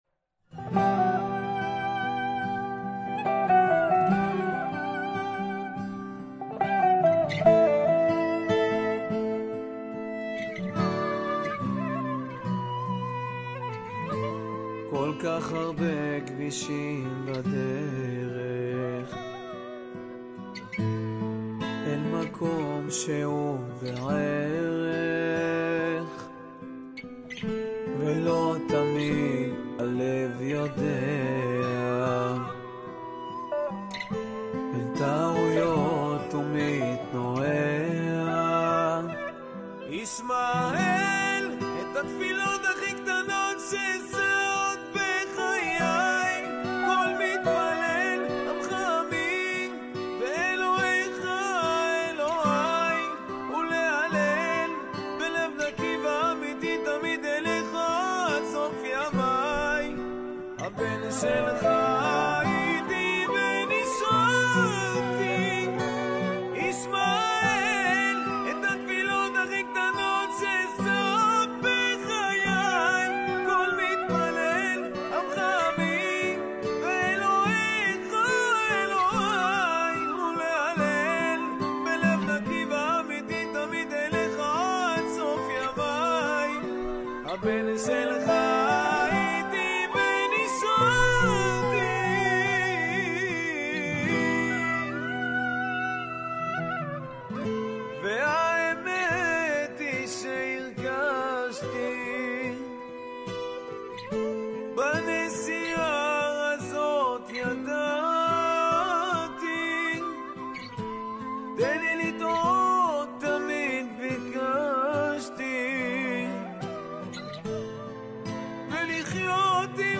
קאבר
בלדת תפילה וכניעה לפני בורא עולם.
עיבוד אקוסטי